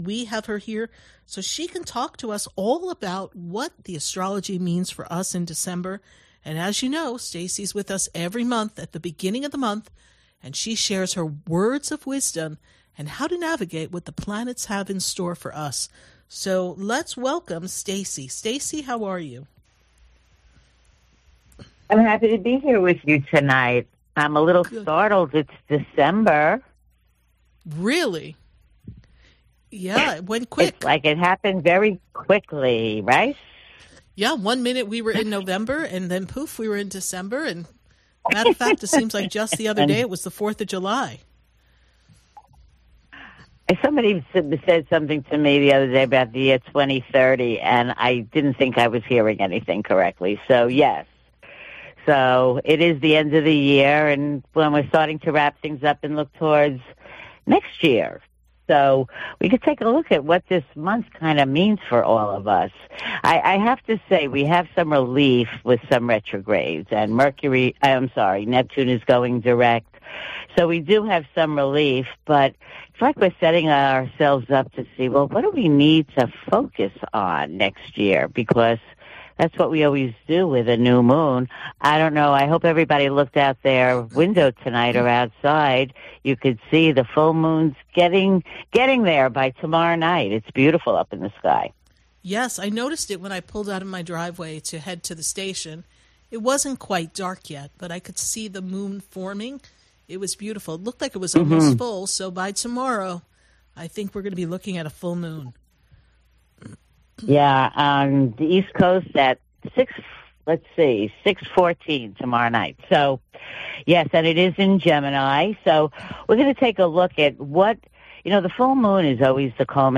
LIVE on the radio